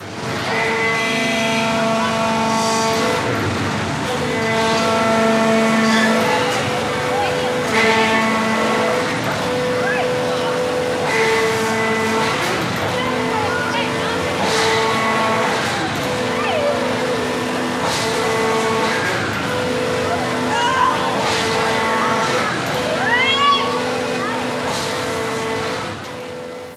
Parque de atracciones: el barco